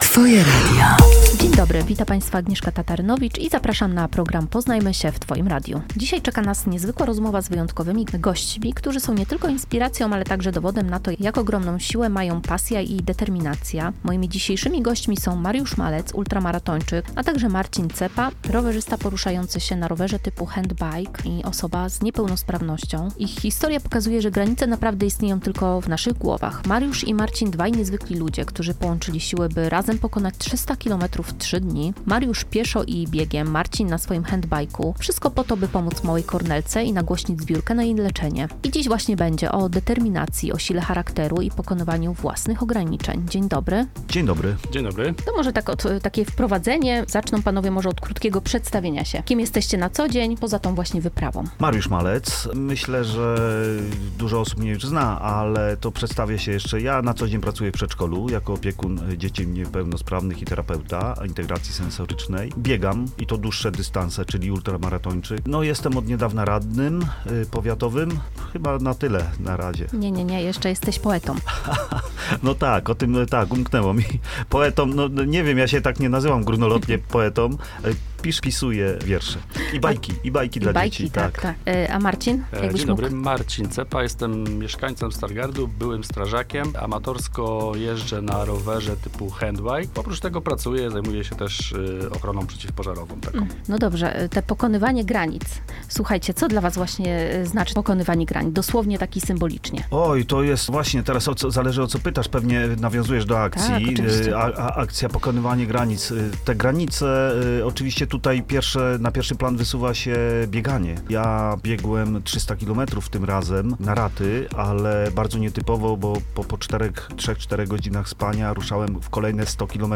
Dziś w programie zapraszamy na spotkanie z dwoma niezwykłymi mężczyznami: